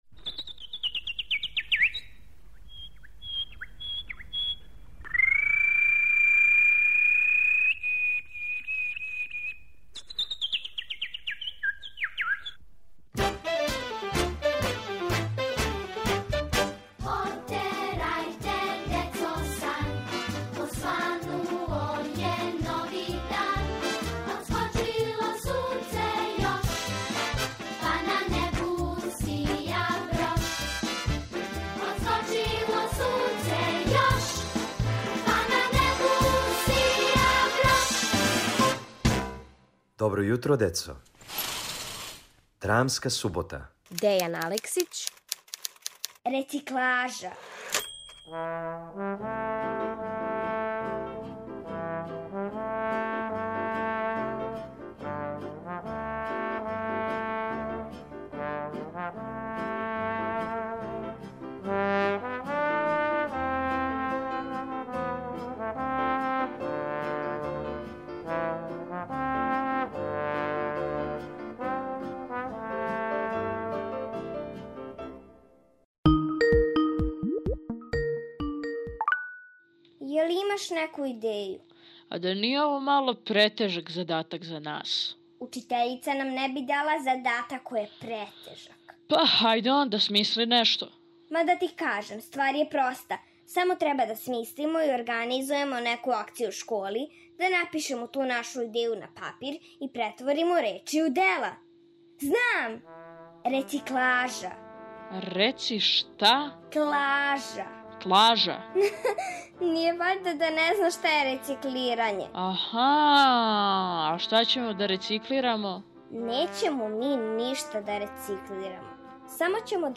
У нашој драмској суботи слушате кратку драму Дејана Алексића у којој ће те сазнати како ће се два ђака изборити са идејом рециклаже...